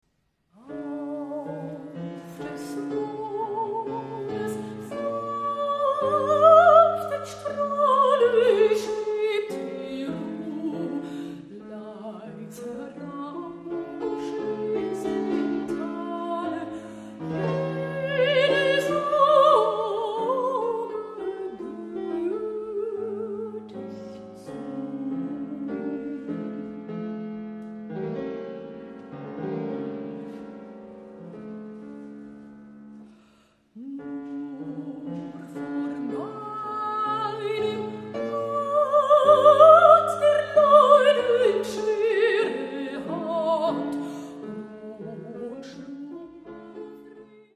Mezzosopran
Hammerflügel